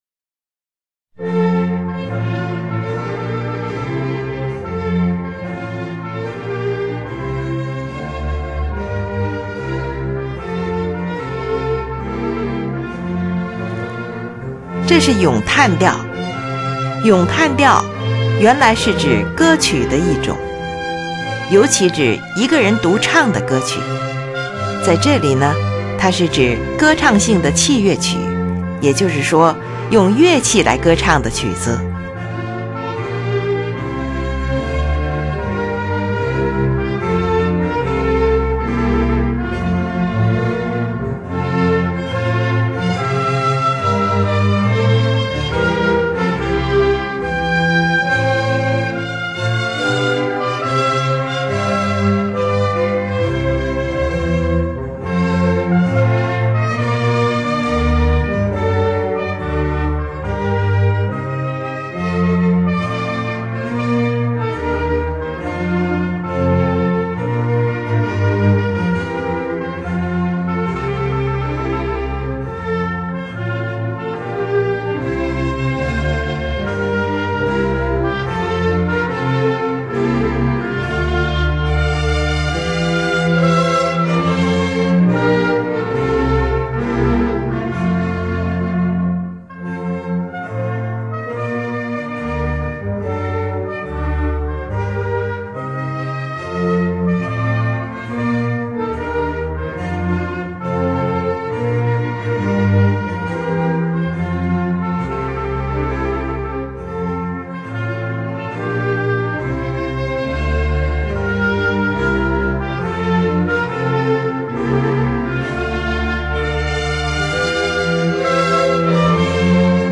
是一部管弦乐组曲
乐器使用了小提琴、低音提琴、日耳曼横笛、法兰西横笛、双簧管、圆号、小号等